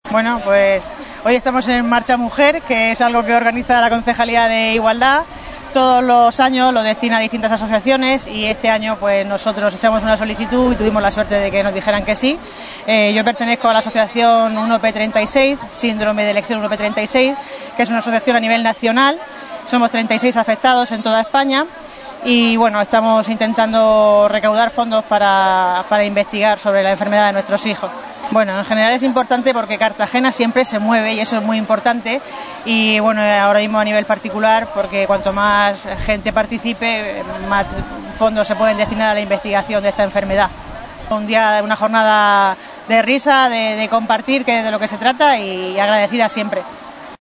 Audio: Declaraciones de la alcaldesa en MarchaMujer (MP3 - 366,87 KB)